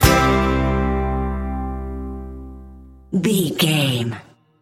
Ionian/Major
acoustic guitar
banjo
bass guitar
drums
Pop Country
country rock
bluegrass
uplifting
driving
high energy